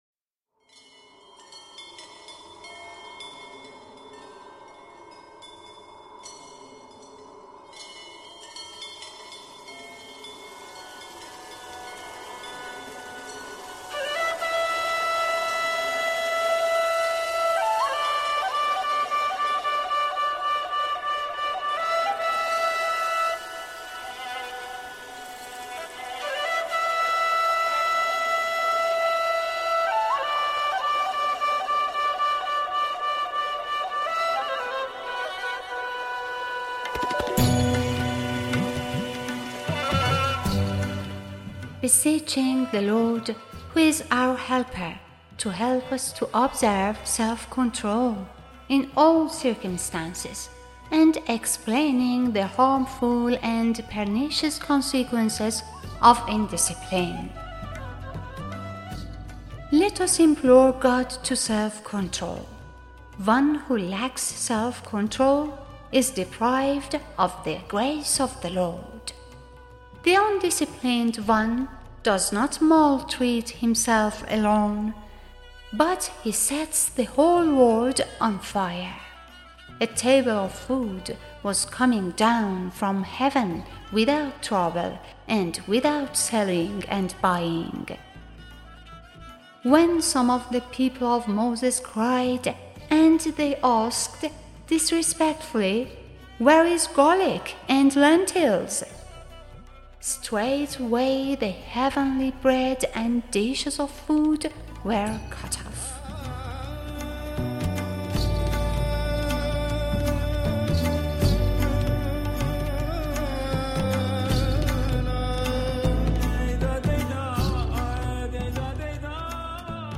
Narrator and Producer